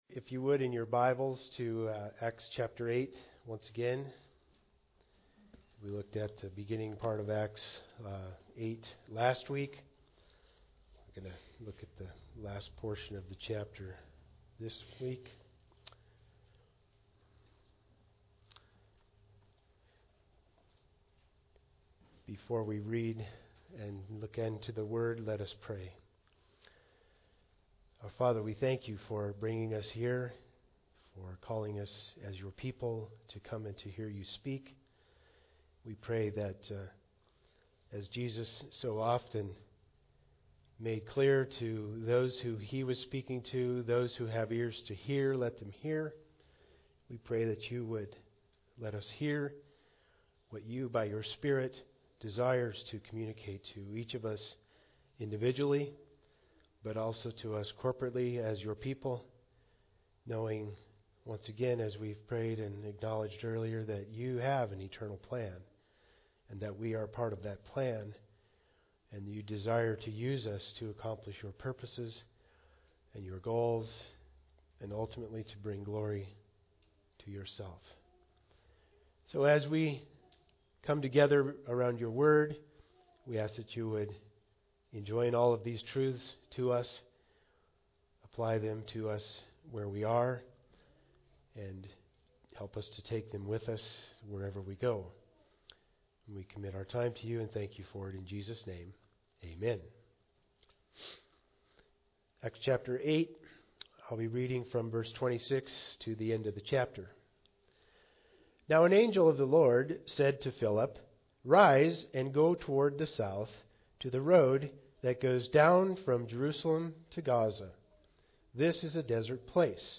Passage: Acts 8:26-40 Service Type: Sunday Service